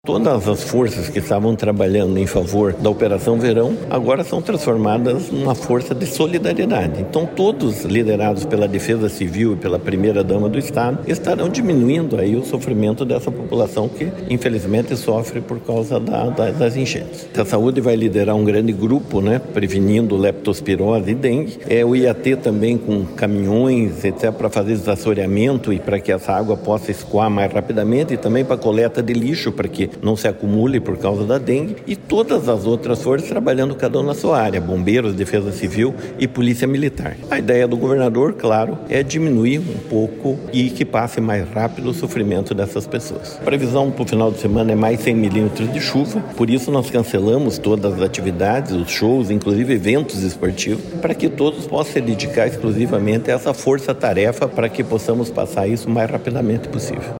Sonora do secretário do Esporte, Helio Wirbiski, sobre a atuação dos profissionais contratados para o Verão Maior Paraná no auxílio à população afetada pelas chuvas